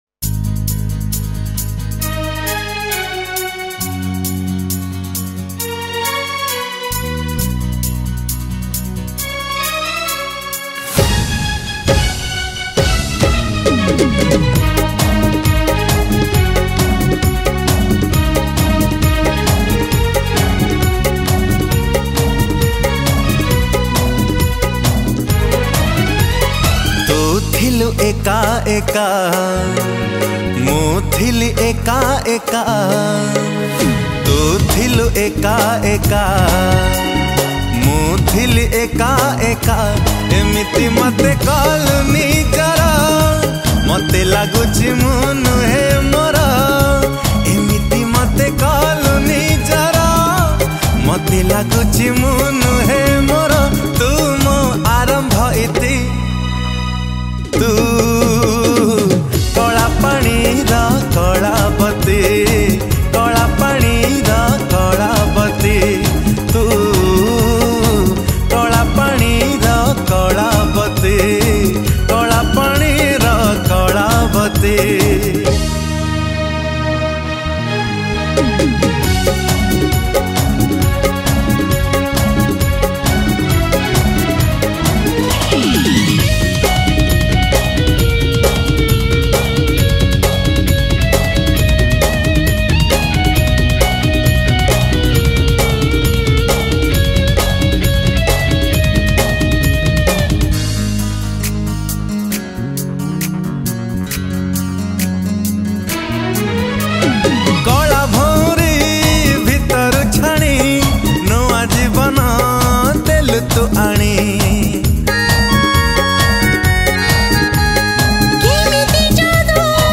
Category: Odia Jatra Full Song